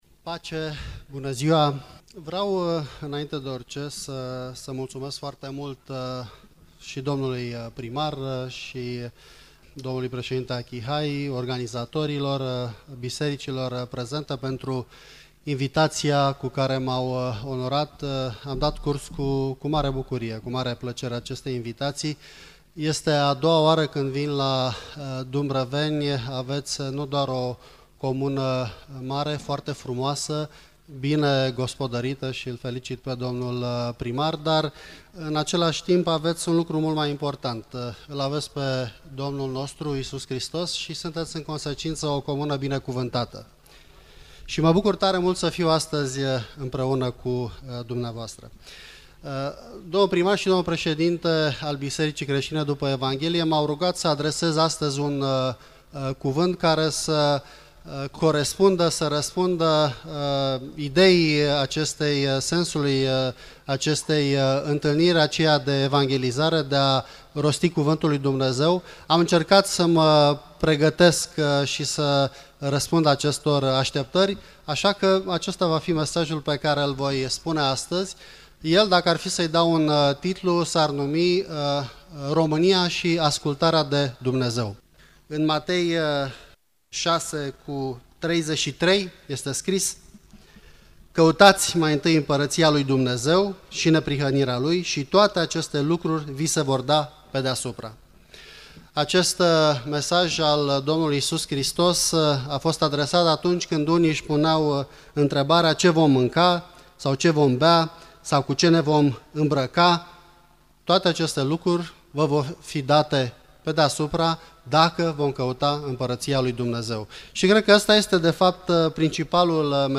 Senatorul Titus Corlăţean a fost invitat să aibă un mesaj în cadrul evenimentului organizat de Primăria localităţii Dumbrăveni din Suceava în colaborare cu Biserica Creştină după Evanghelie. Evenimentul a avut loc în Parcul Central din localitate pe data de 20 iulie.
titus_corlatean_dumbraveni.mp3